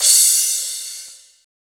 VEC3 Crash